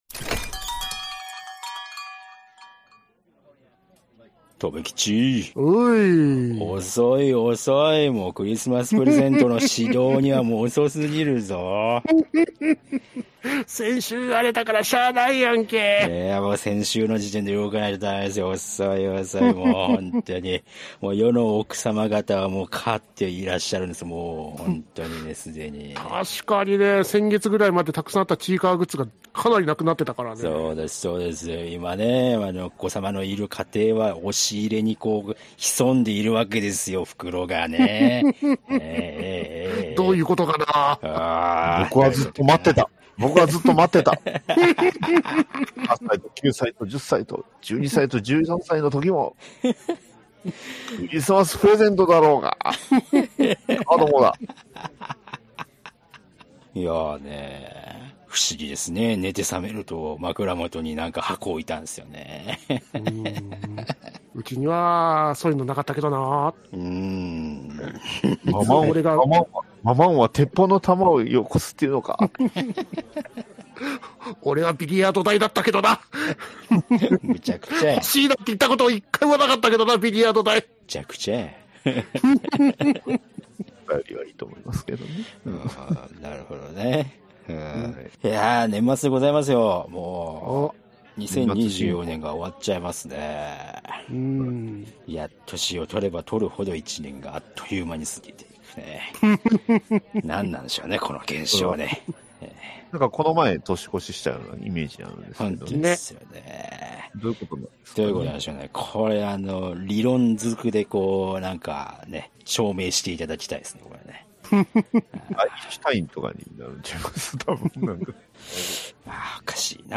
1 第４８夜 俺にも我慢の限界がある「ハイスピードエトワールどうなっとんねん」回 1:11:14 Play Pause 4d ago 1:11:14 Play Pause 「あとで再生する」 「あとで再生する」 リスト 気に入り 気に入った 1:11:14 ファイルサイズ目安48MB ダウンロードはWi-Fi環境を推奨しております 一部音声が聞き取りにくい部分がございます、ご了承をお願いいたします このブラウザでは再生できません。